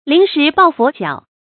臨時抱佛腳 注音： ㄌㄧㄣˊ ㄕㄧˊ ㄅㄠˋ ㄈㄛˊ ㄐㄧㄠˇ 讀音讀法： 意思解釋： 原意為年老信佛，以求保佑，有臨渴掘井之意。